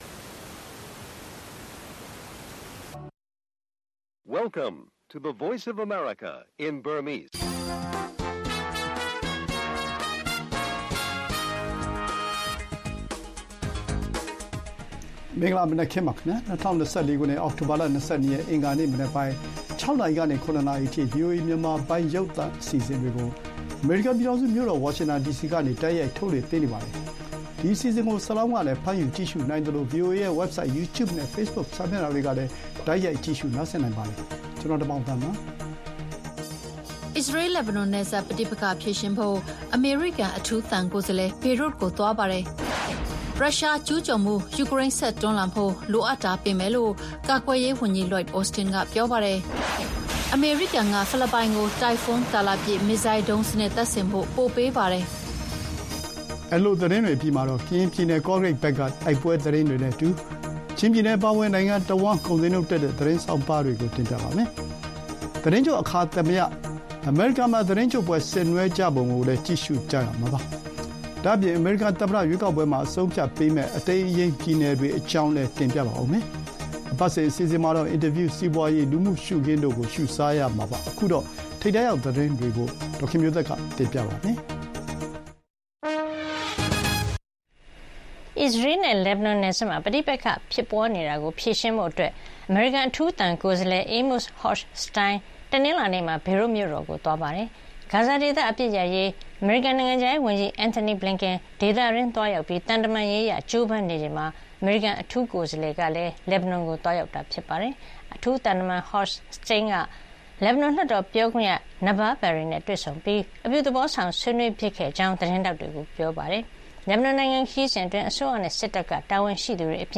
ဗွီအိုအေ မြန်မာနံနက်ခင်း အောက်တိုဘာ ၂၂ ရက် ၂၀၂၄ အစ္စရေး လက်ဘနွန်ပြဿနာ ဖြေရှင်းဖို့ အမေရိကန် ကြိုးစားတာ၊ မြဝတီခရိုင်တွင်းစစ်ရေး တင်းမာနေတာနဲ့ အမျိုးသား ညီညွတ်ရေး အစိုးရ (NUG) ဝန်ကြီး ဒေါက်တာ ဇော်ဝေစိုးနဲ့ တွေ့ဆုံ မေးမြန်းခန်း၊ စီးပွါးရေးနဲ့ လူမှုရှုခင်း စတဲ့အပတ်စဉ် အစီအစဉ် တွေကိုတင်ပြပေးမှာပါ။